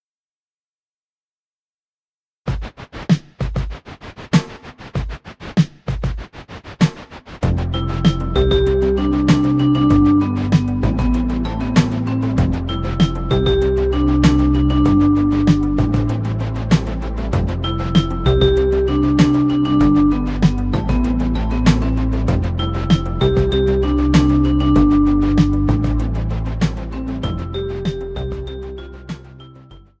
Voicing: French Horn and Audio Online